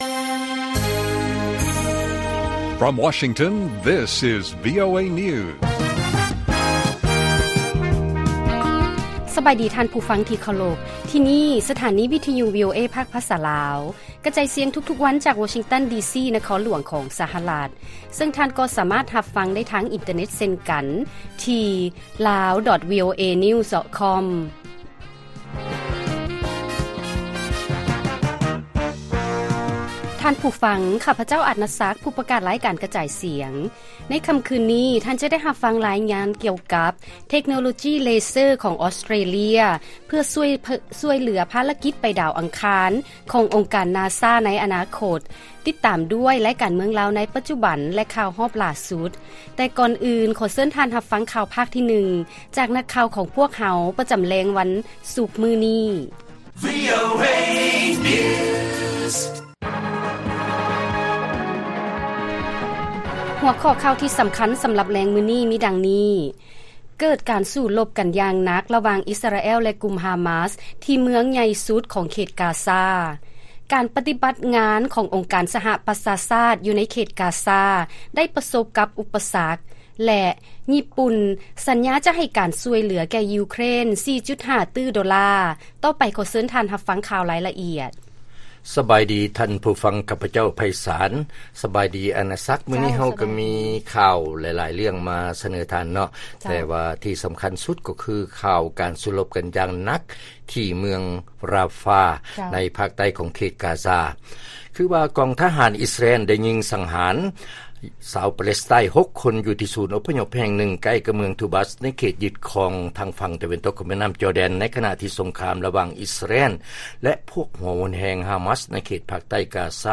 ລາຍການກະຈາຍສຽງຂອງວີໂອເອ ລາວ: ເກີດການສູ້ລົບກັນຢ່າງໜັກ ລະຫວ່າງອິສຣາແອລ ແລະກຸ່ມຮາມາສ ທີ່ ເມືອງໃຫຍ່ສຸດ ຂອງເຂດກາຊາ